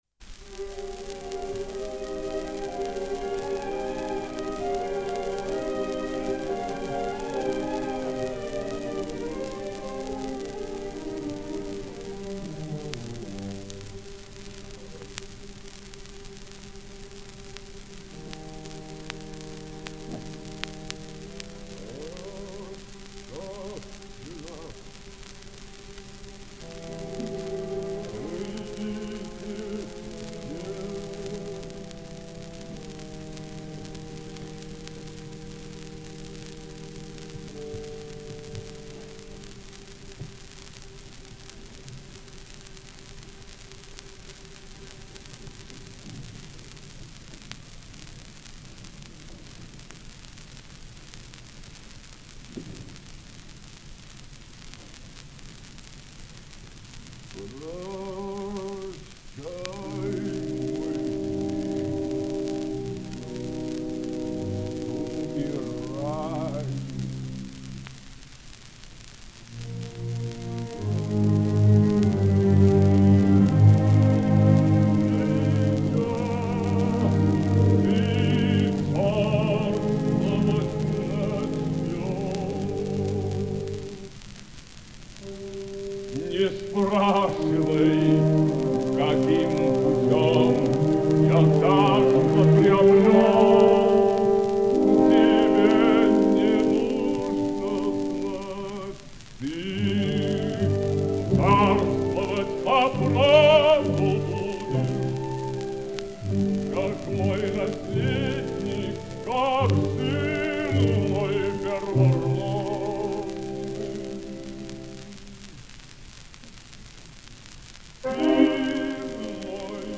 Произведения в исполнении Ф. И. Шаляпина